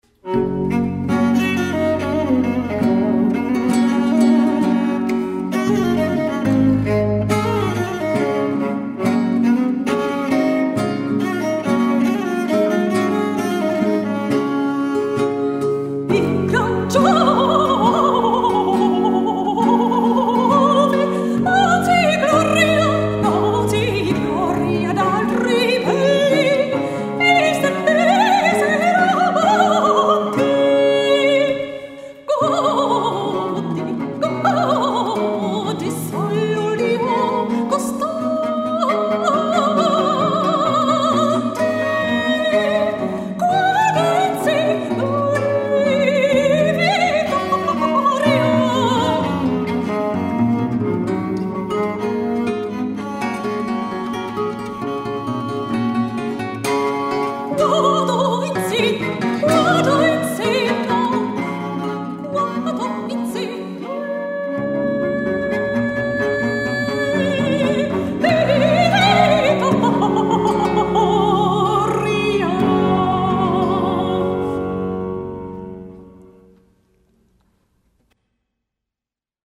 Concert à Notre Dame de la Salette
Madrigal « La vittoria »
Soprane
Viole de Gambe
Guitare baroque et Archiluth
Viola Pomposa